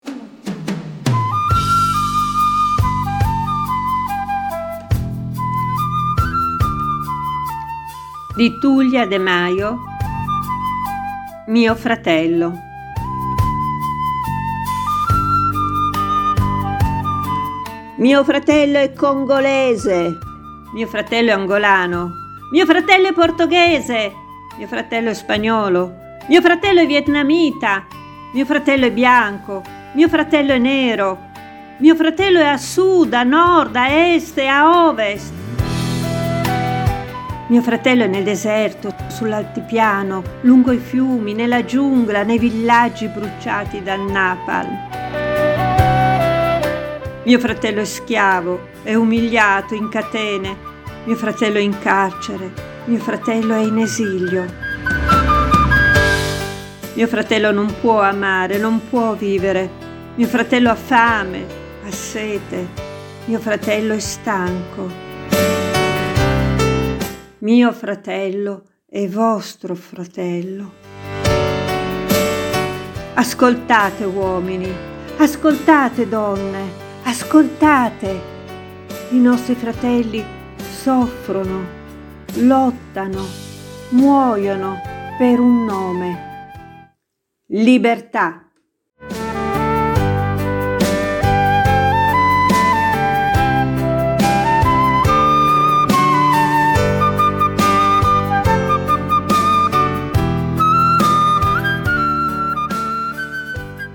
The peruvian flute